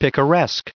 added pronounciation and merriam webster audio
1798_picaresque.ogg